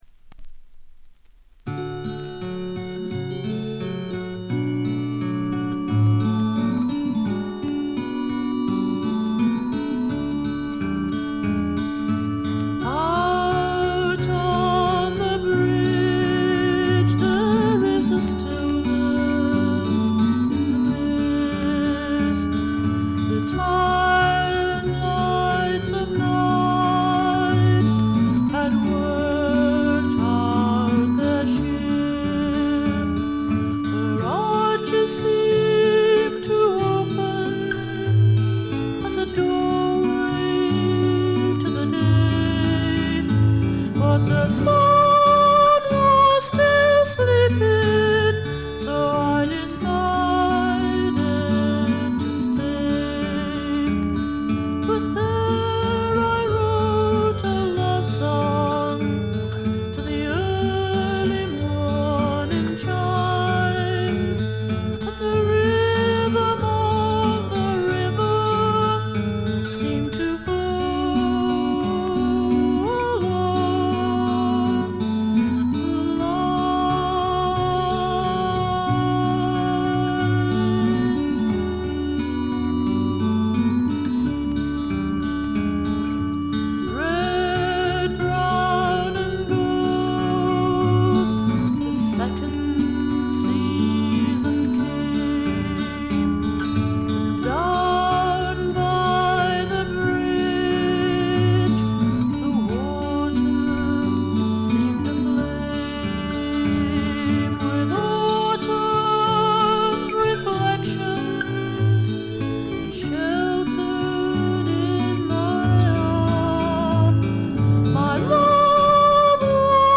バックグラウンドノイズやや多め大きめですが、鑑賞を妨げるほどのノイズはありません。
試聴曲は現品からの取り込み音源です。